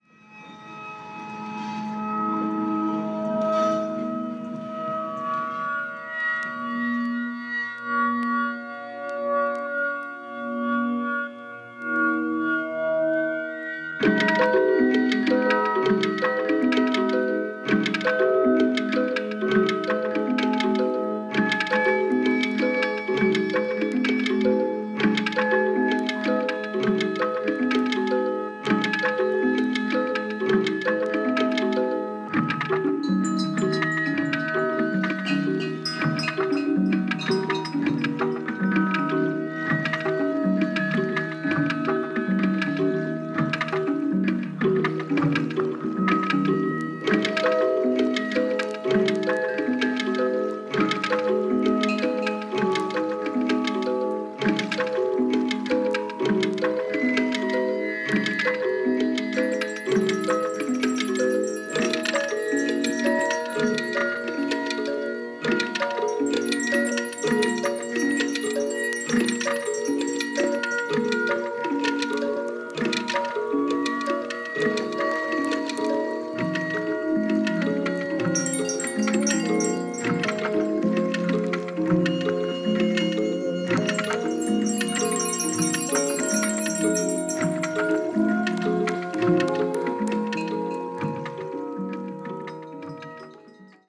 中国でのライブ2枚組です！！！